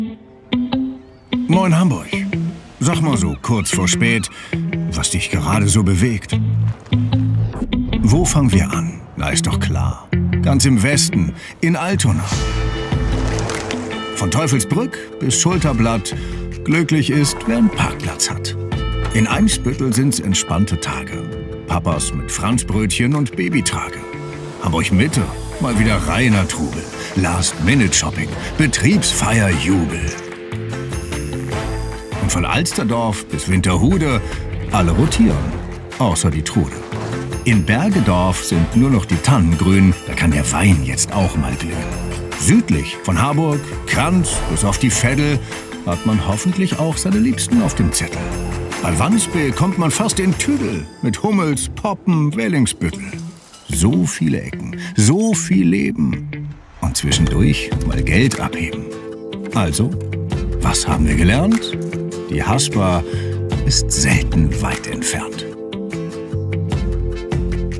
markant, dunkel, sonor, souverän, sehr variabel, plakativ
Mittel plus (35-65)
Commercial (Werbung), Presentation